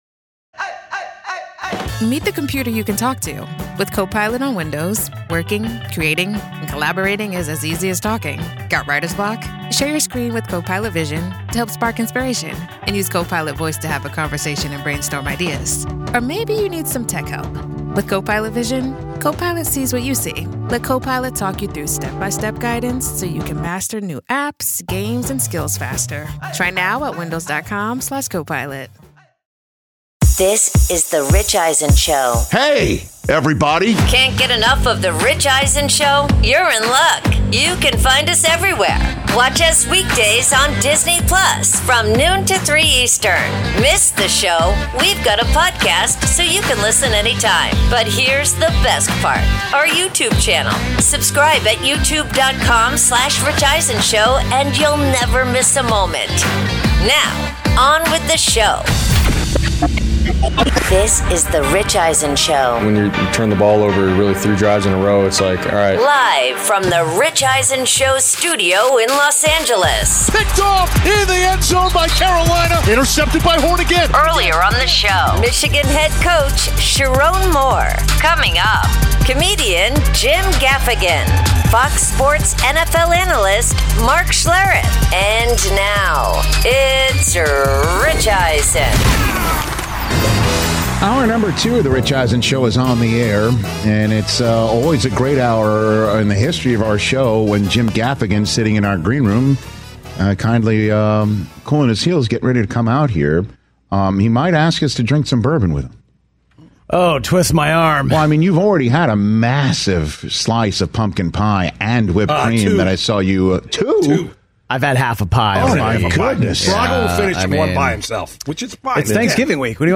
Hour 2: ‘Overreaction Tuesday,’ plus Comedian Jim Gaffigan In-Studio Podcast with Rich Eisen